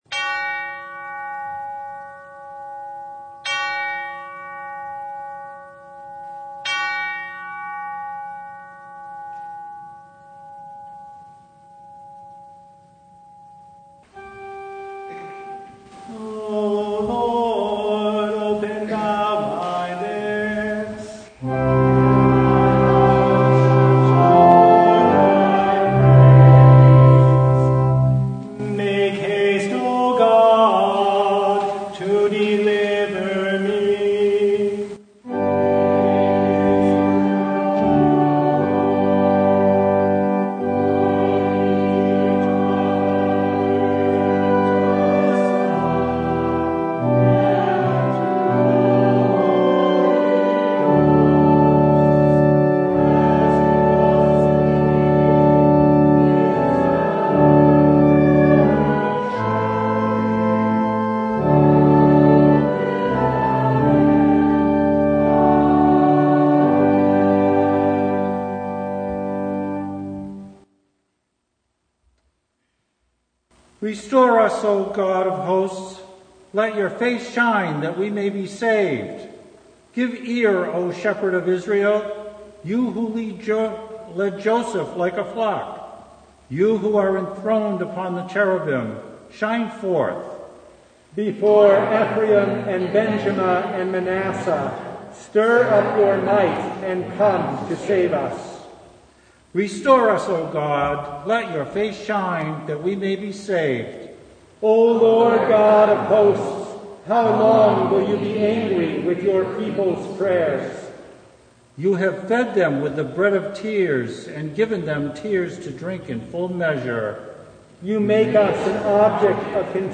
Service Type: Advent Vespers